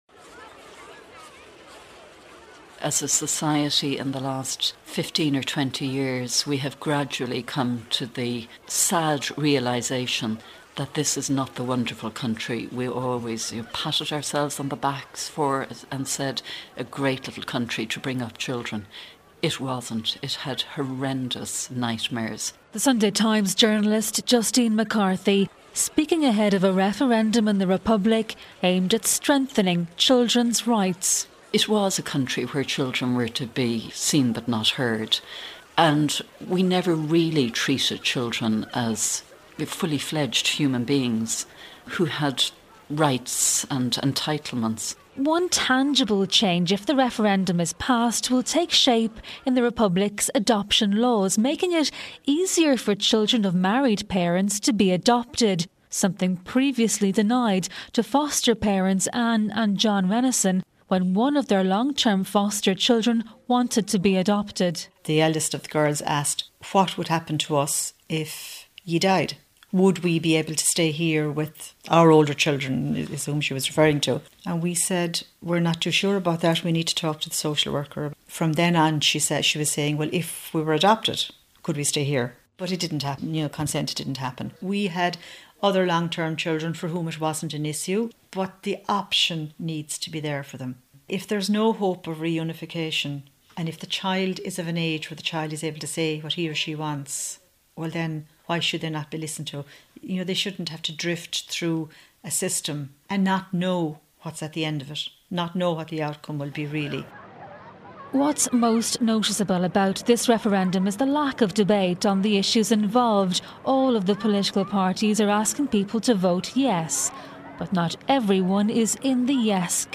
(Broadcast on Good Morning Ulster Nov 2 2012)